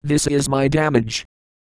Worms speechbanks
oinutter.wav